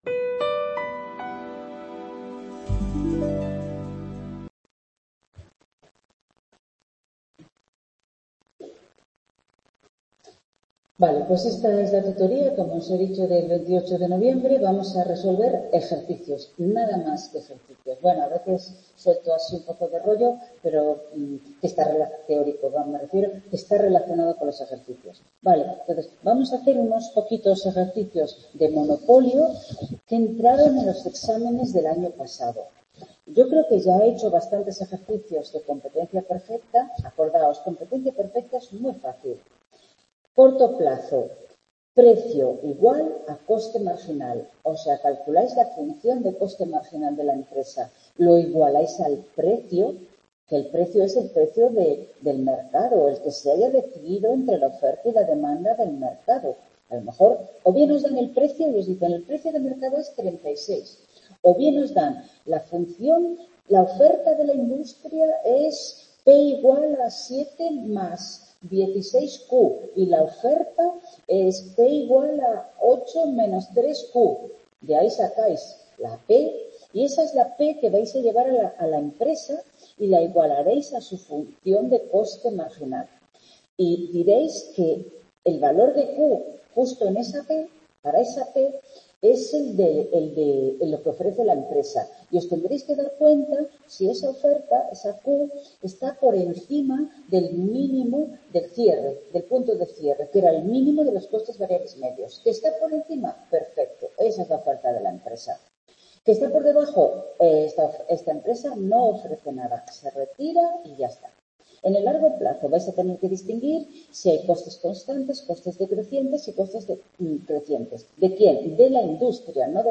La última parte de la tutoría grabada tenía el problema de que no se reconocía ni la cámara, ni el micrófono del aula y, por lo tanto, la comunicación se hizo a través del chat.